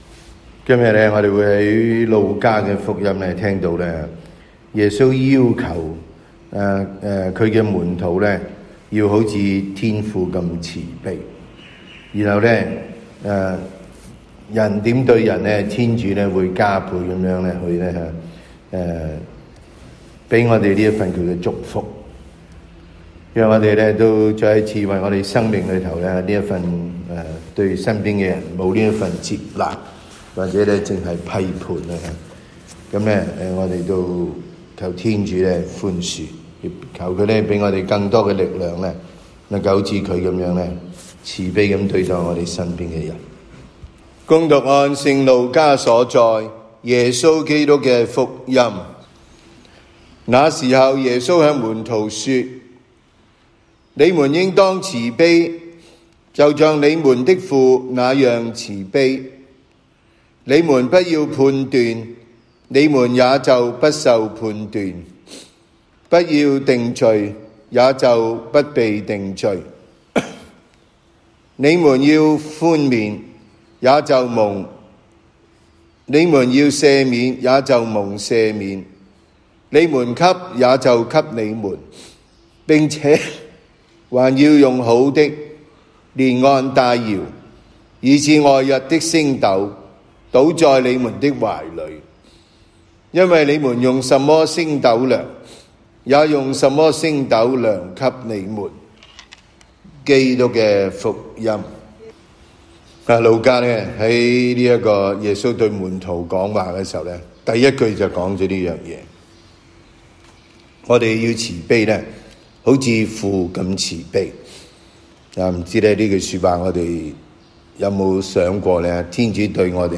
每日講道及靈修講座